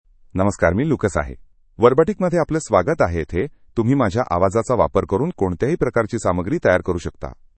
Lucas — Male Marathi AI voice
Lucas is a male AI voice for Marathi (India).
Voice sample
Listen to Lucas's male Marathi voice.
Male
Lucas delivers clear pronunciation with authentic India Marathi intonation, making your content sound professionally produced.